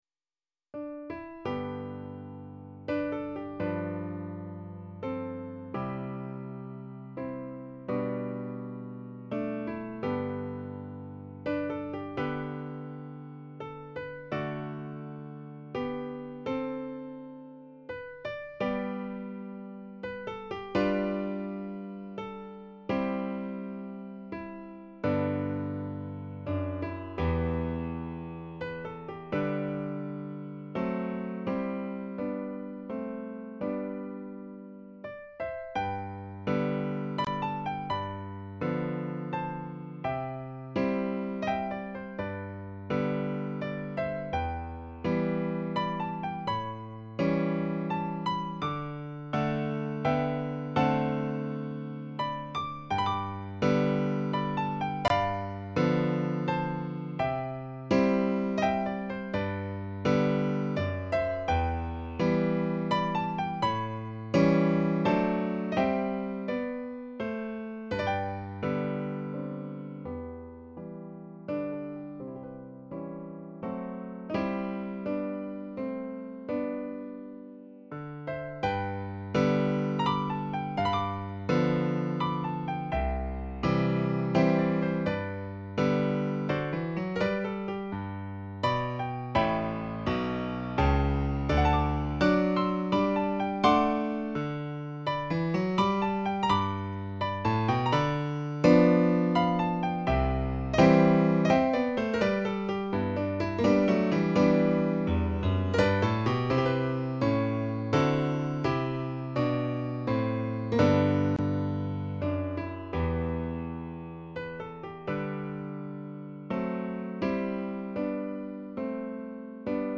Here is free sheet music to my piano arrangement of  Amazing Grace.
piano-amazing-grace.mp3